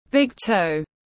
Ngón chân cái tiếng anh gọi là big toe, phiên âm tiếng anh đọc là /ˌbɪɡ ˈtəʊ/.
Big toe /ˌbɪɡ ˈtəʊ/